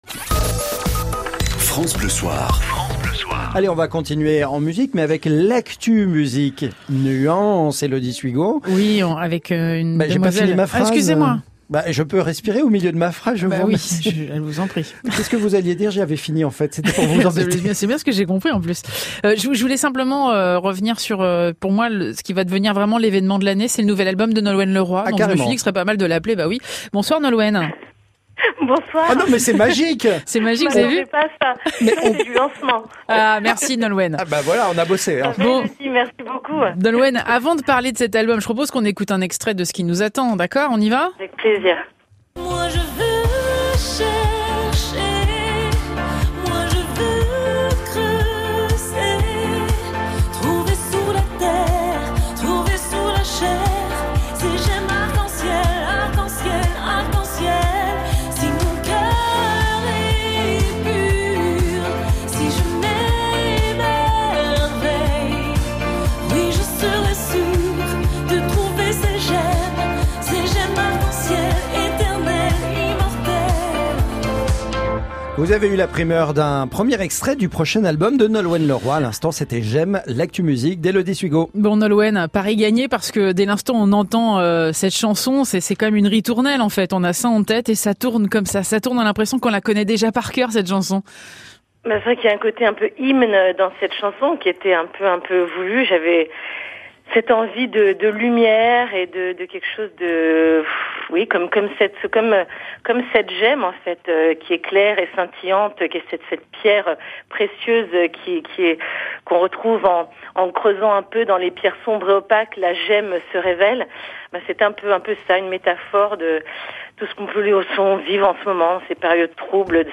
Nolwenn au telephone chez France Bleu hier sir
Très belle interview de Nolwenn